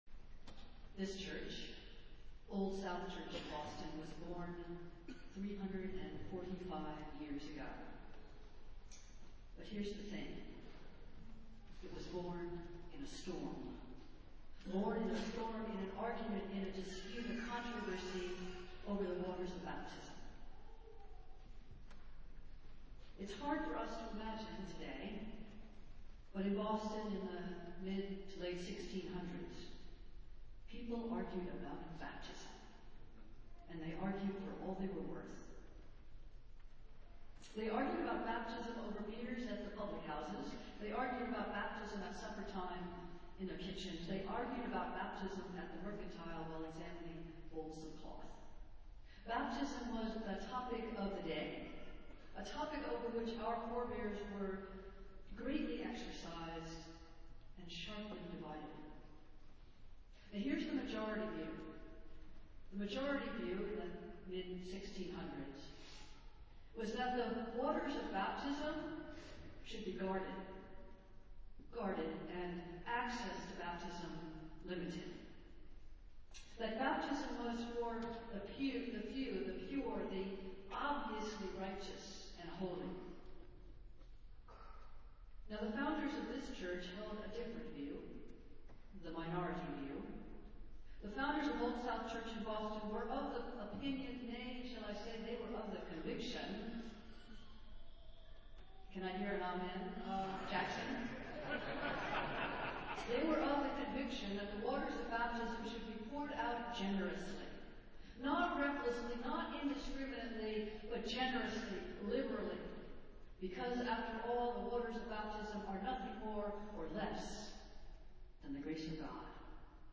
Festival Worship - Martin Luther King, Jr. Sunday